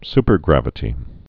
(spər-grăvĭ-tē)